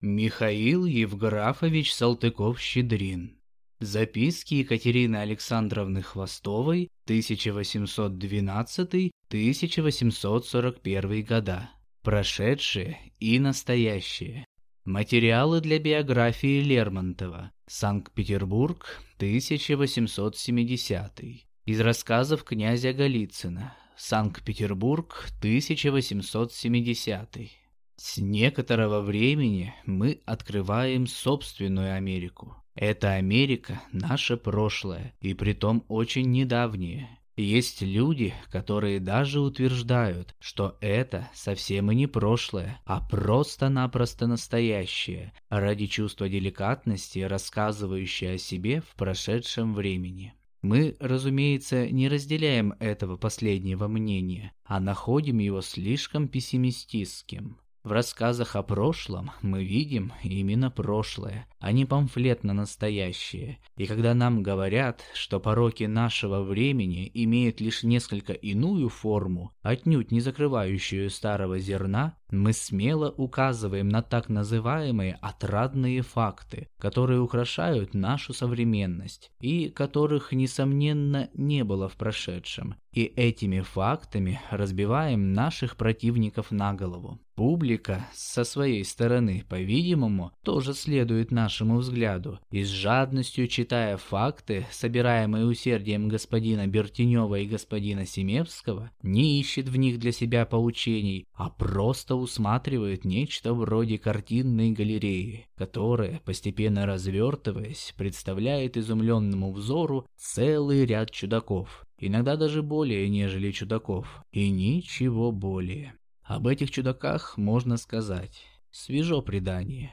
Аудиокнига Записки Е. А. Хвостовой. 1812–1841…